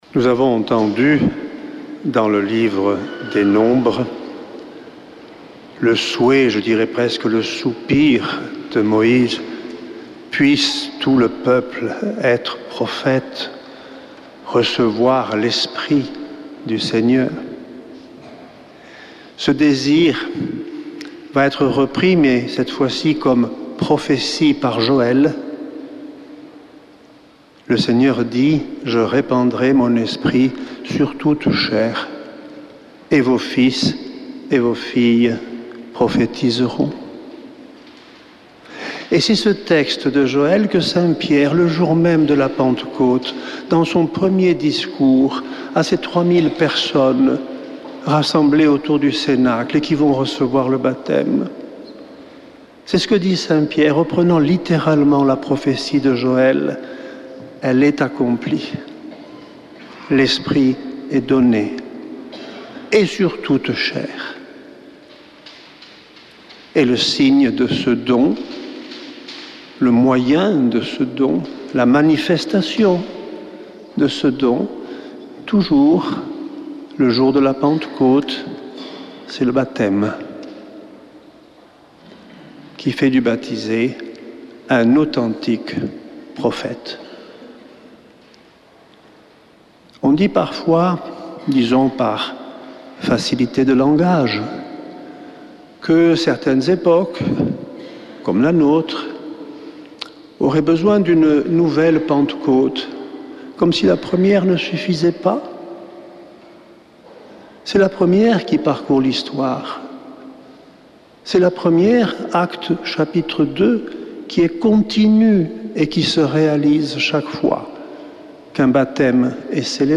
Messe depuis le couvent des Dominicains de Toulouse
Homélie du 29 septembre